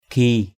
/kʱi:/ 1.